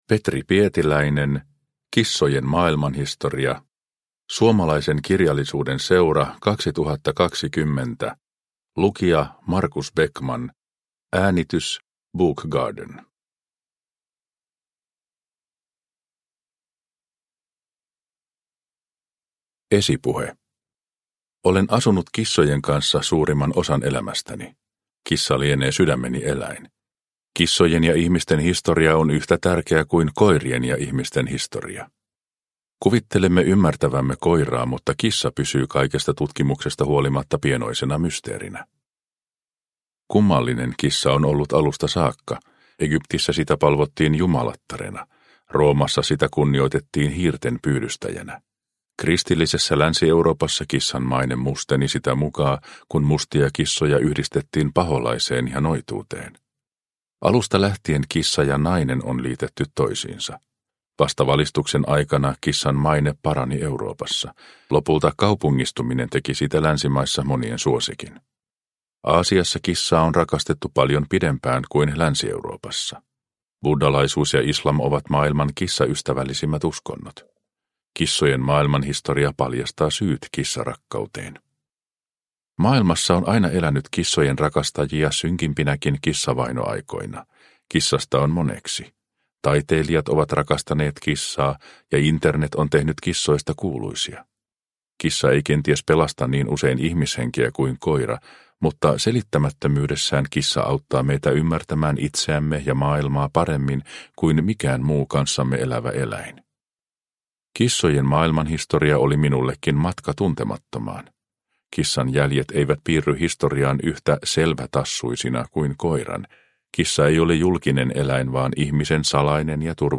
Kissojen maailmanhistoria – Ljudbok – Laddas ner